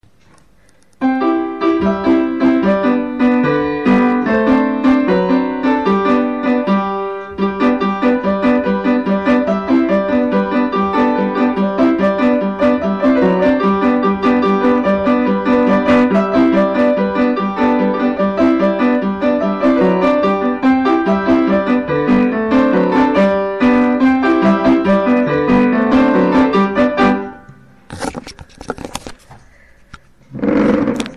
חוץ מזה נחמד מאוד,שבעה ארזים,ובלי הזזת כיסאות פעם הבאה :-P
23:15 נחמד, הקלטה קצת "גסה" לצערי.